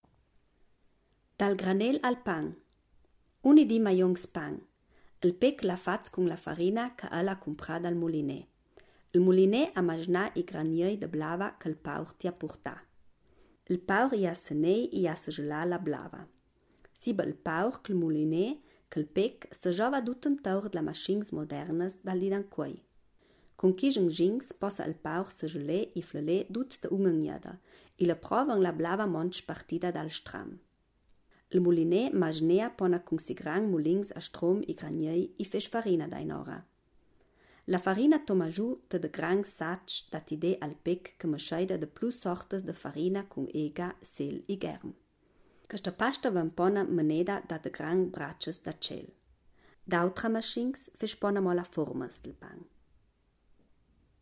Ladino gardenese